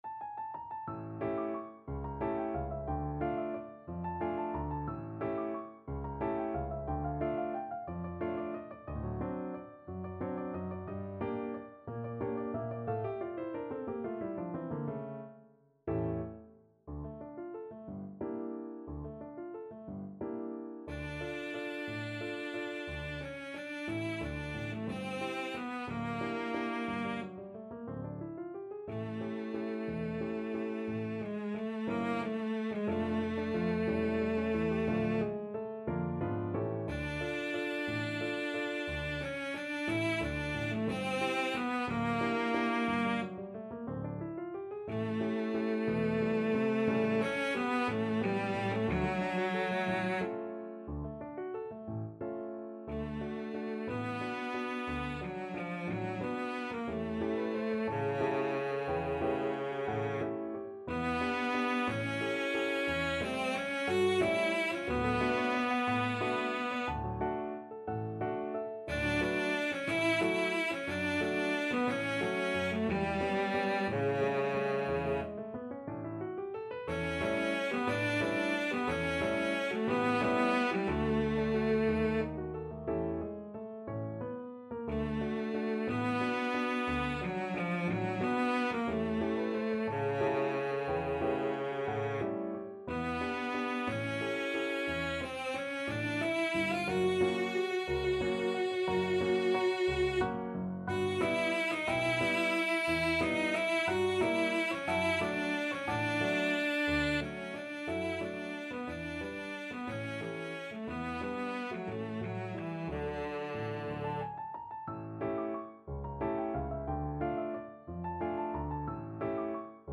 3/4 (View more 3/4 Music)
Allegro movido =180 (View more music marked Allegro)
Classical (View more Classical Cello Music)
Mexican